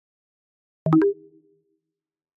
Звуковые эффекты iMac и MacBook